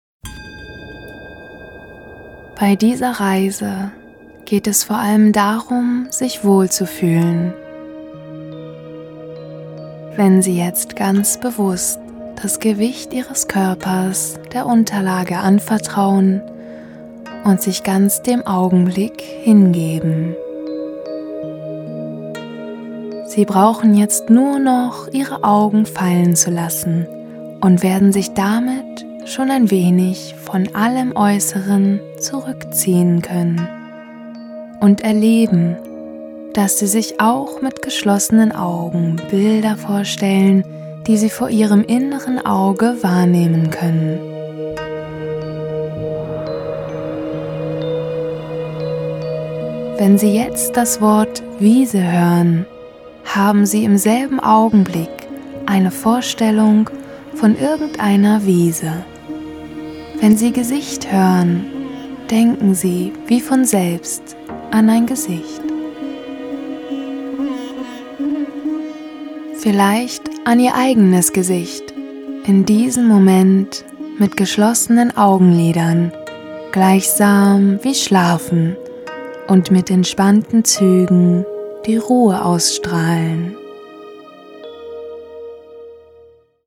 Sprecherin deutsch
norddeutsch
Sprechprobe: Sonstiges (Muttersprache):
german female voice over artist